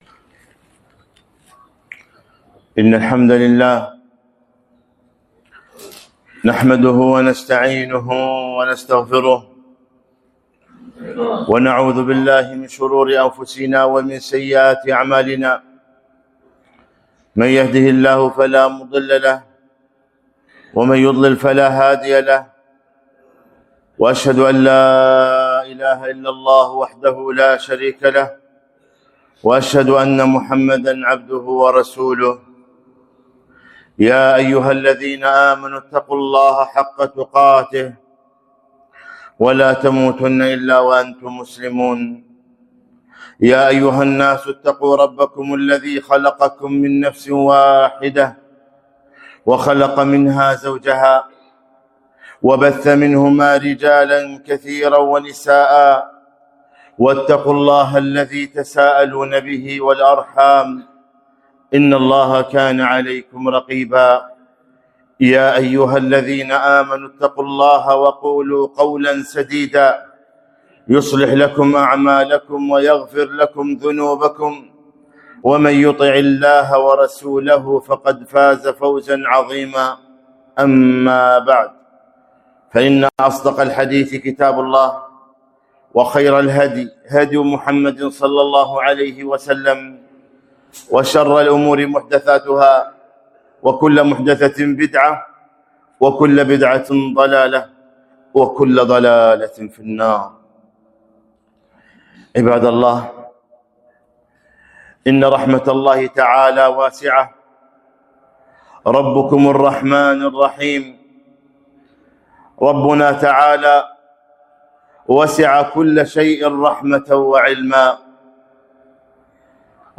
خطبة - أسباب رحمة الله